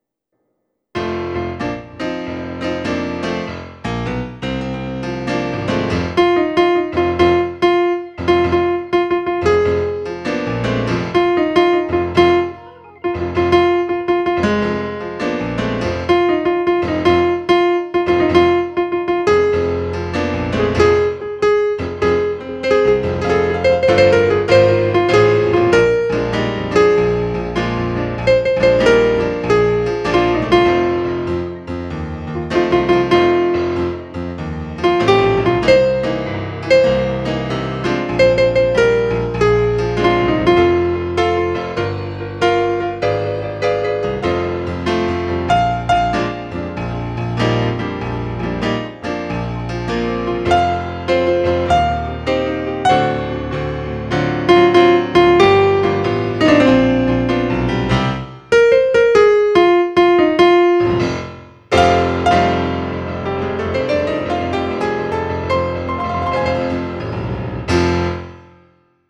Woman Soprano melody2
Woman-Soprano-melody2.mp3